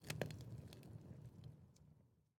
fire_crackle2.ogg